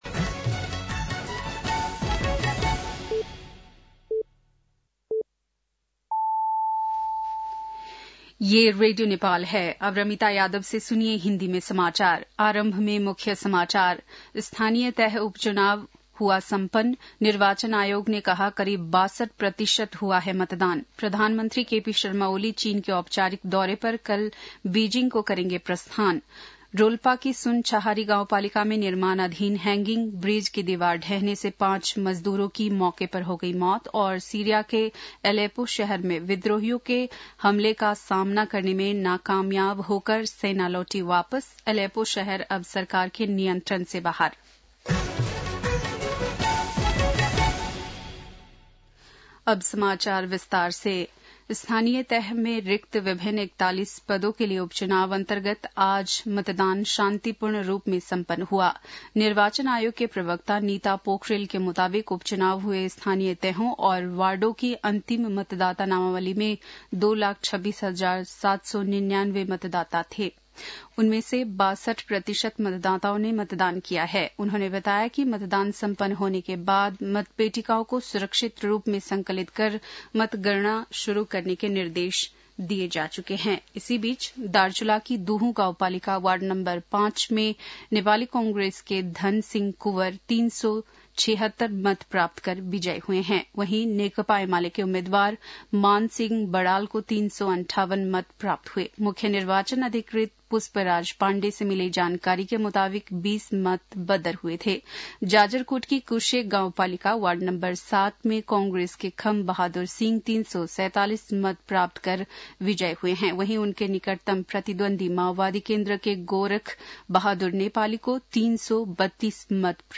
बेलुकी १० बजेको हिन्दी समाचार : १७ मंसिर , २०८१
10-pm-hindi-news-8-16.mp3